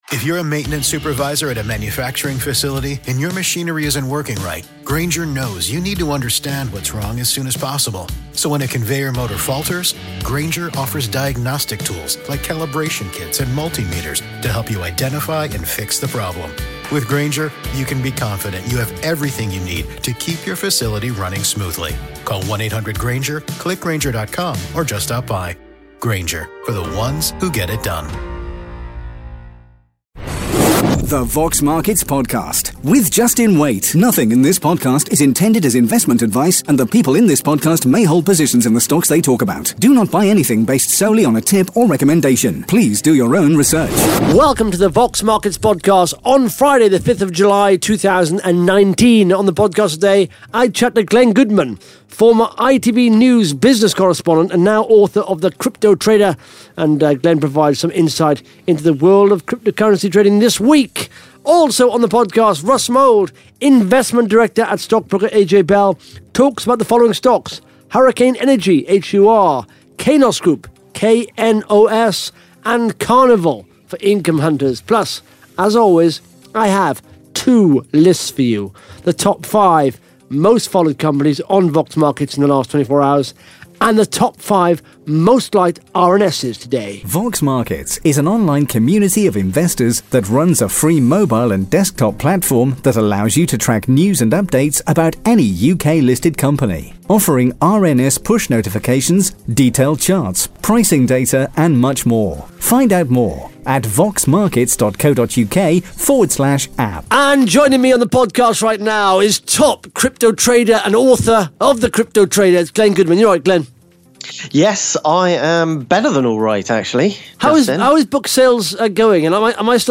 (Interview starts at 17 minutes 14 seconds) Plus the Top 5 Most Followed Companies & the Top 5 Most Liked RNS’s on Vox Markets in the last 24 hours.